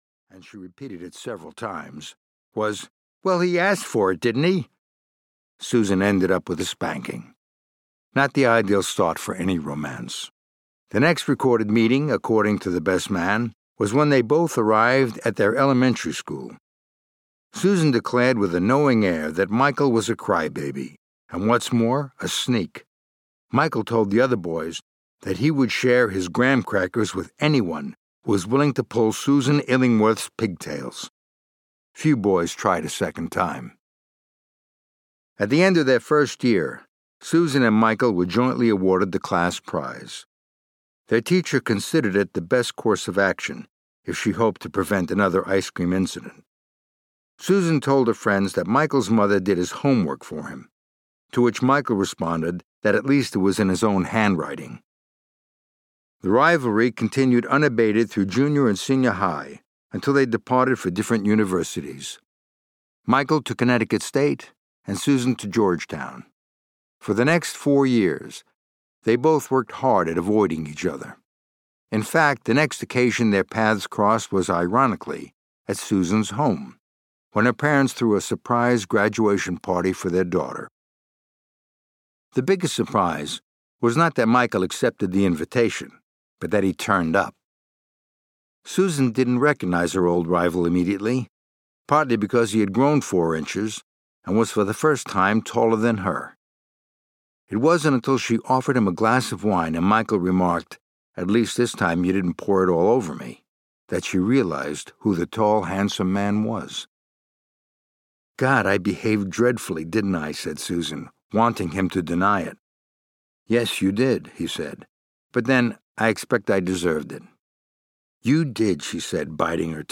Sons of Fortune (EN) audiokniha
Ukázka z knihy